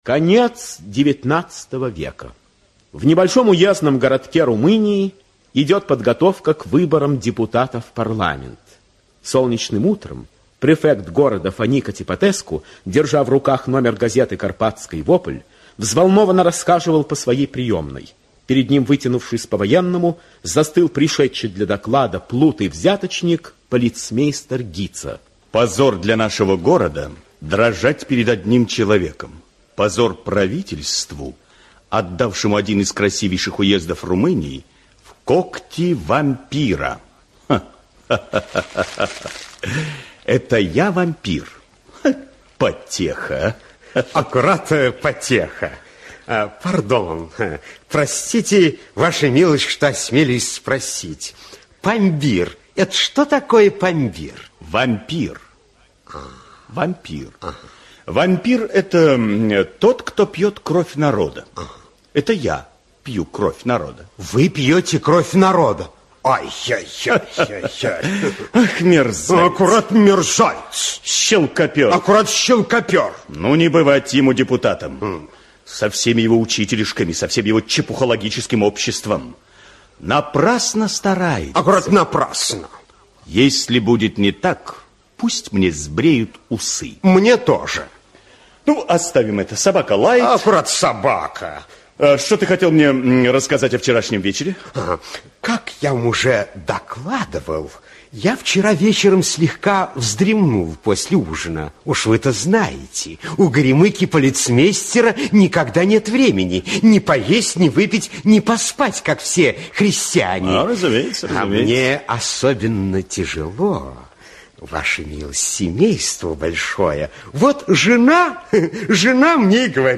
Аудиокнига Потерянное письмо (спектакль) | Библиотека аудиокниг
Aудиокнига Потерянное письмо (спектакль) Автор Ион Караджиале Читает аудиокнигу Актерский коллектив.